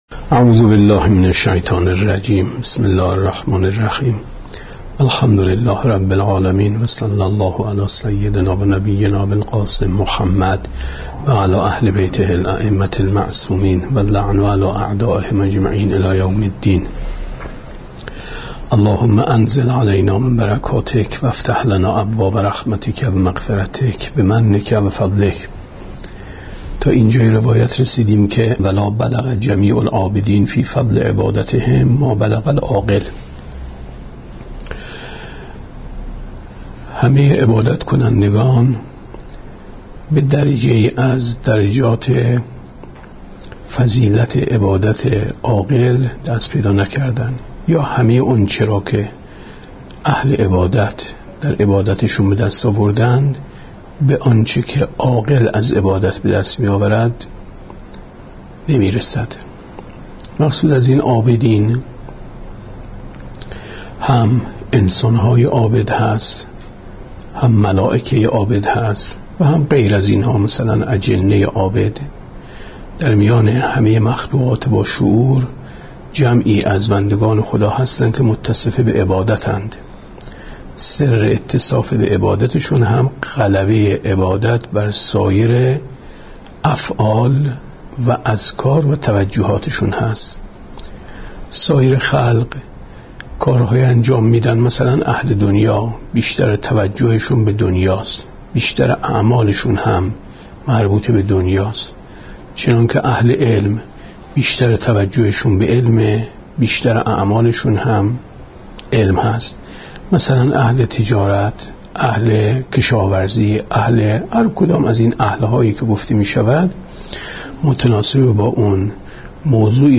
گفتارهای ماه مبارک رمضان 1436 ـ جلسه هفتم ـ 8/ 4/ 94 ـ شب سیزدهم ماه رمضان